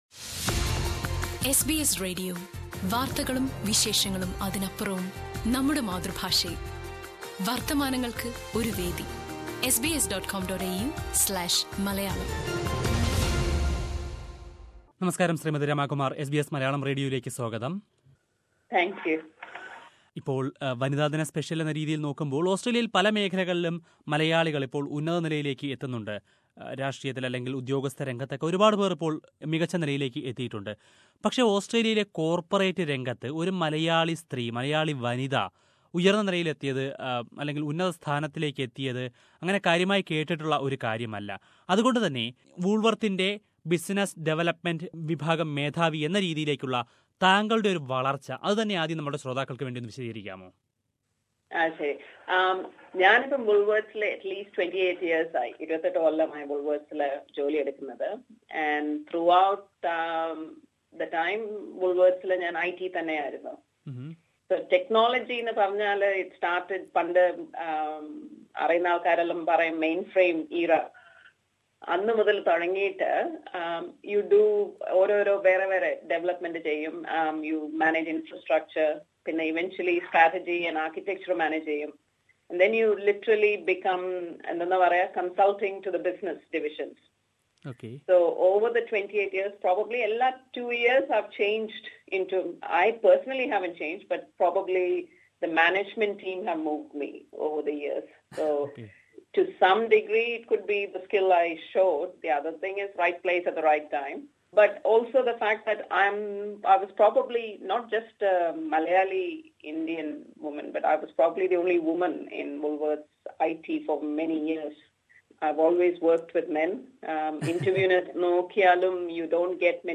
അഭിമുഖം